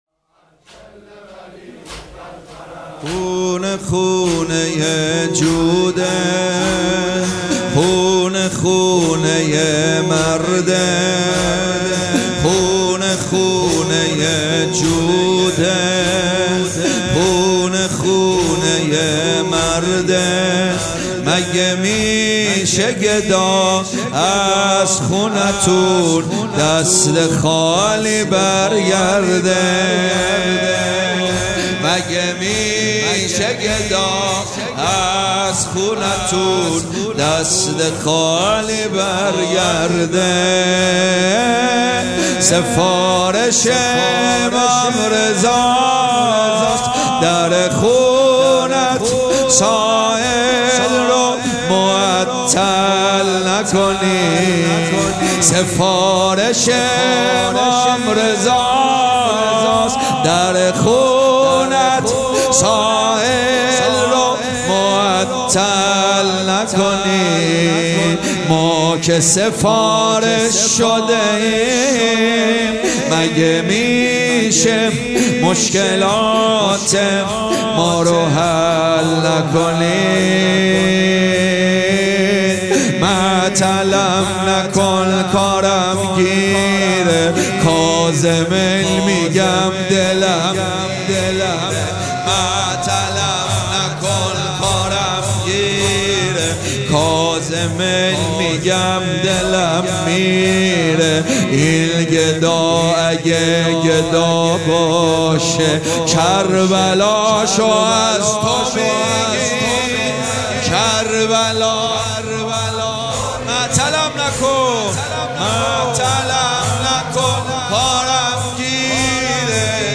مداحی به سبک زمینه اجرا شده است.